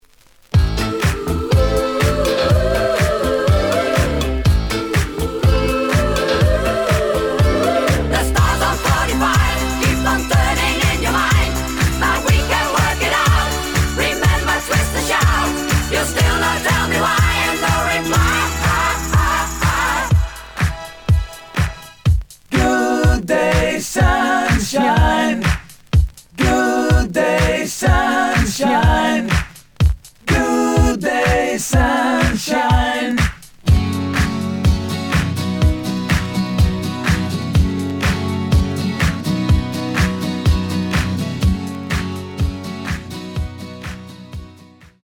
The audio sample is recorded from the actual item.
●Format: 7 inch
●Genre: Disco
Slight edge warp. But doesn't affect playing. Plays good.)